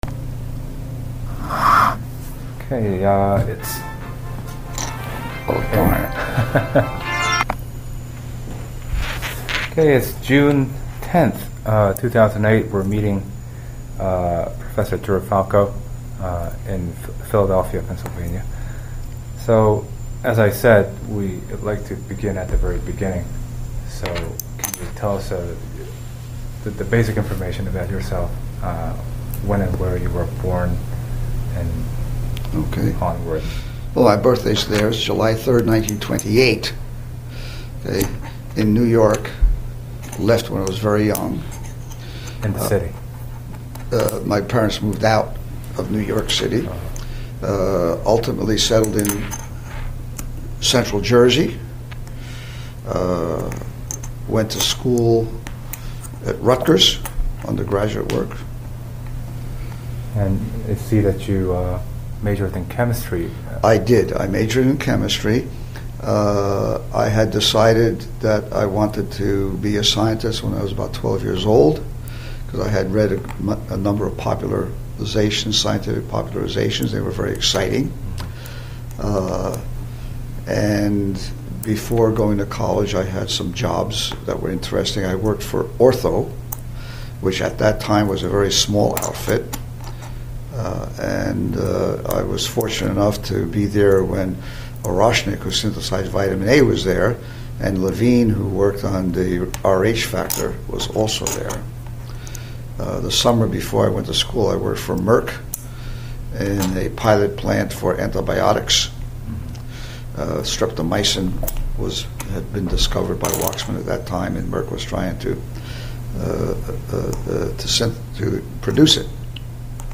Place of interview University of Pennsylvania Pennsylvania--Philadelphia
Genre Oral histories